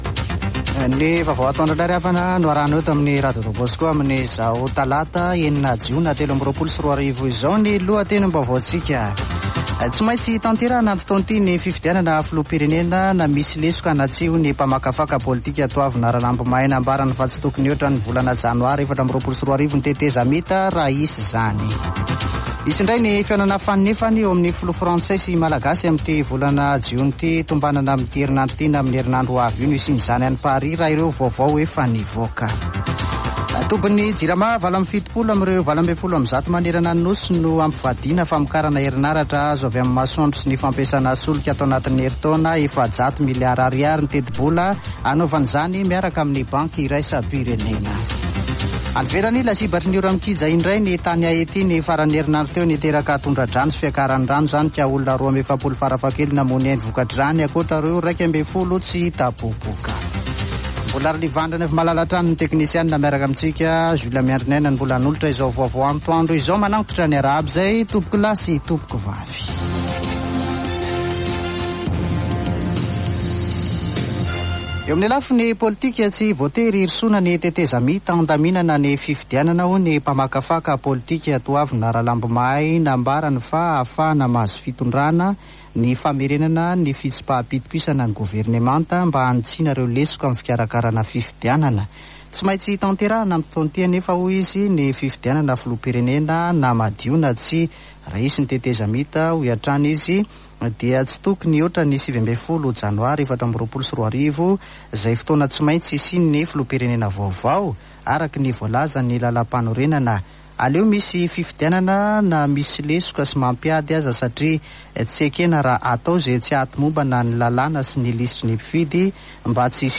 [Vaovao antoandro] Talata 6 jona 2023